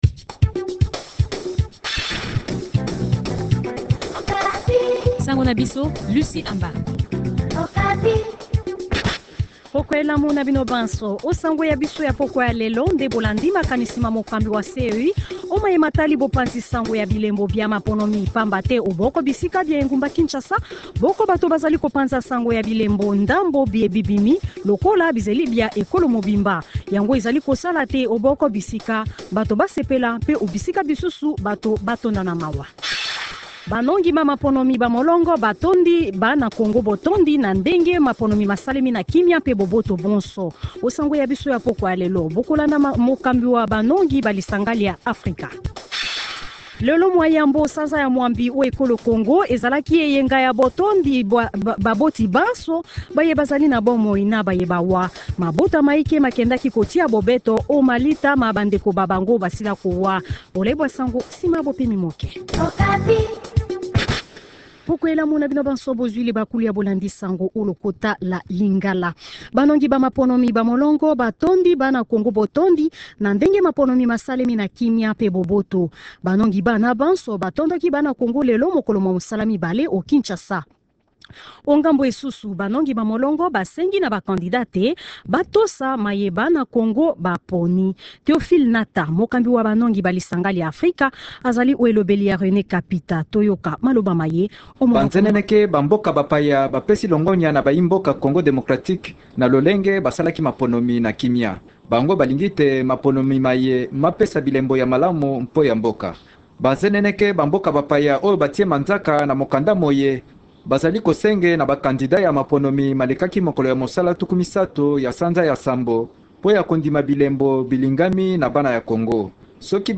Journal Kikongo